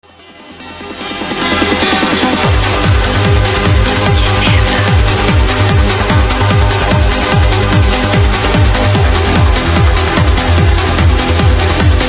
im disgraced. this is trance isnt it?
Its most likely a sped up version of some mix or original.